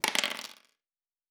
Dice Multiple 2.wav